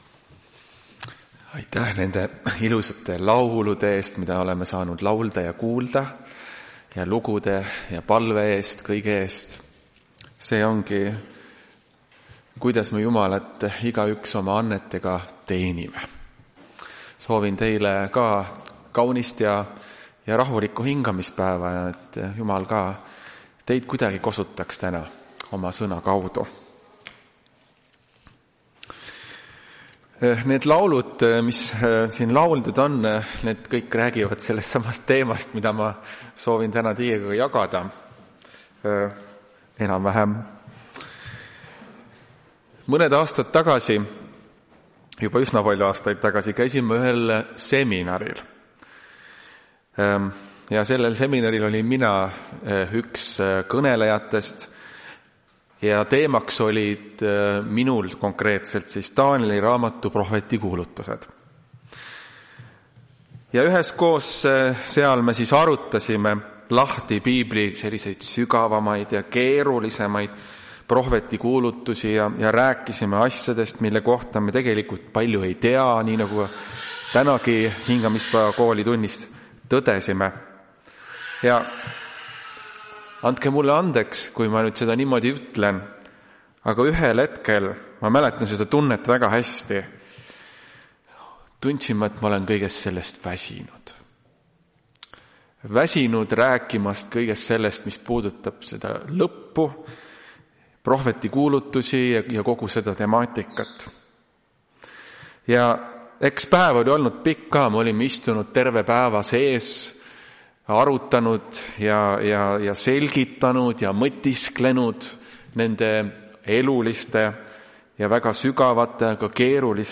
Tartu adventkoguduse 28.06.2025 hommikuse teenistuse jutluse helisalvestis.
Jutlused